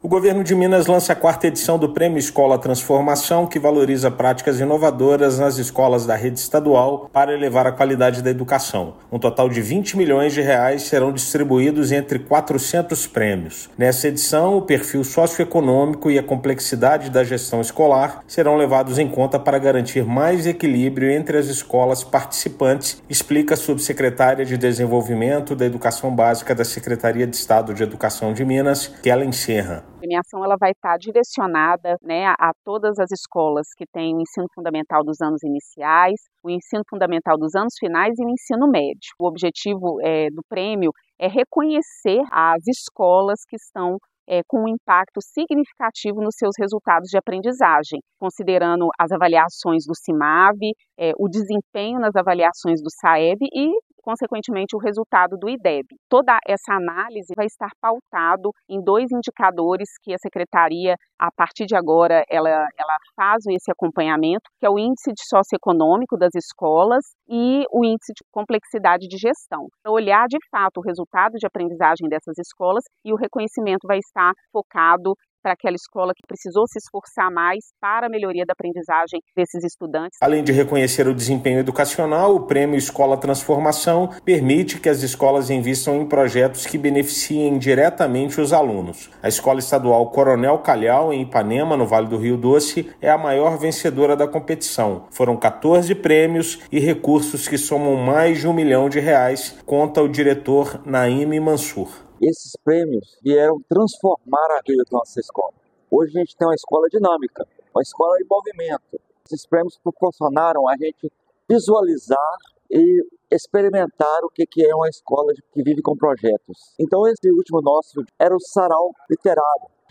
Serão destinados R$ 20 milhões à iniciativa que reconhece escolas que se destacam em importantes indicadores pedagógicos e possibilita o desenvolvimento de projetos que estimulam o protagonismo estudantil. Ouça matéria de rádio.